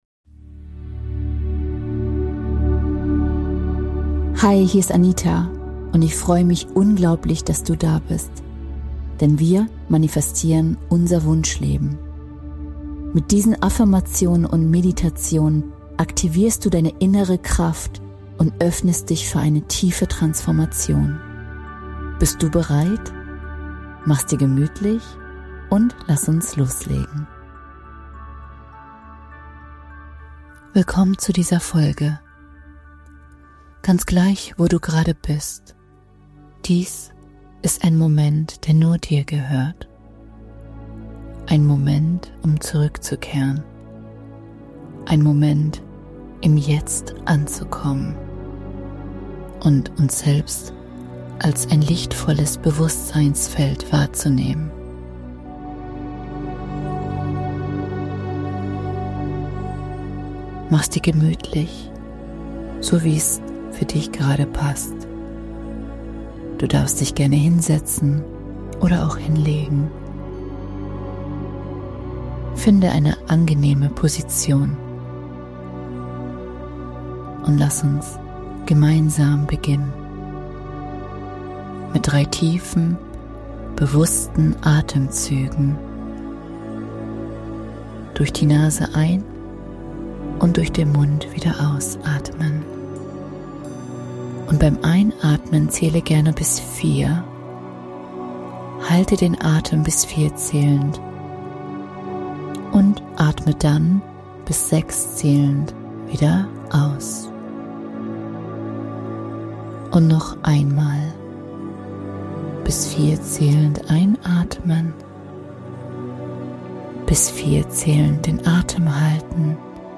In dieser geführten Wintermeditation wirst du eingeladen, dich dem Zauber des Schnees hinzugeben – der Stille, der Klarheit und dem Rückzug dieser besonderen Jahreszeit.